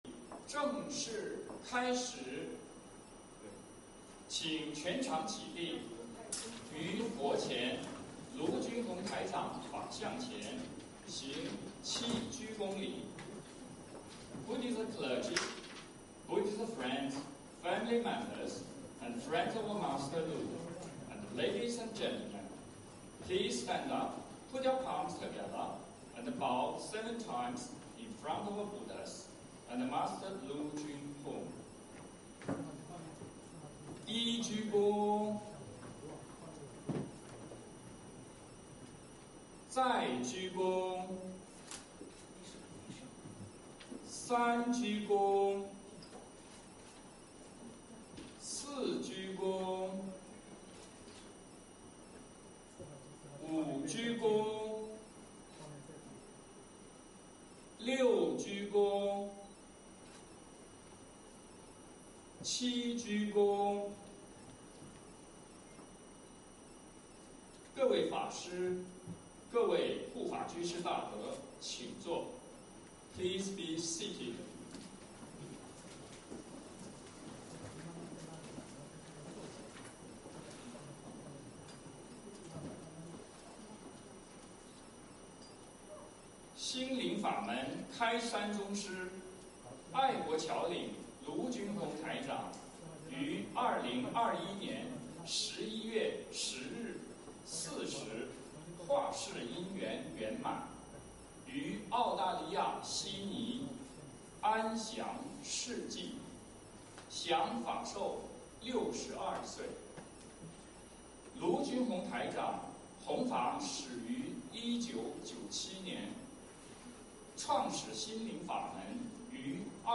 恩师追思赞颂典礼-仪式完整全程！
64_zhuisizansongdianli-yishiwanzhengquancheng.mp3